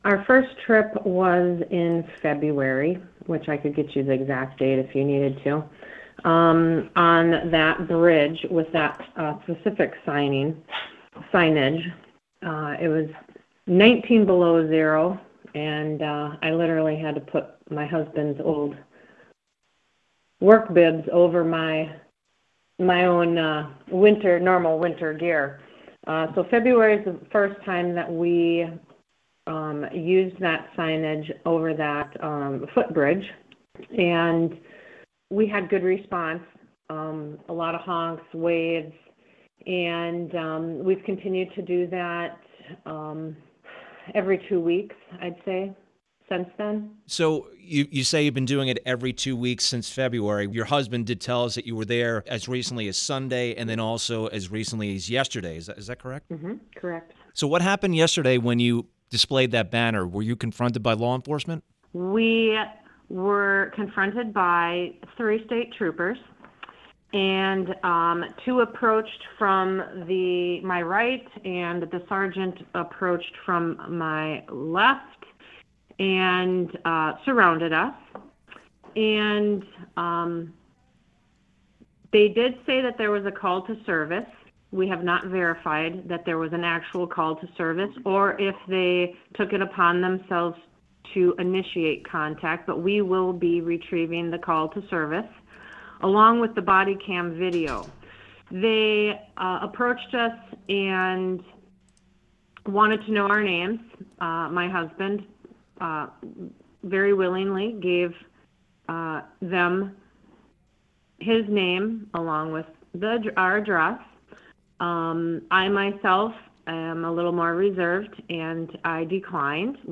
whose husband shouted in the background during the interview that their case can go “all the way to the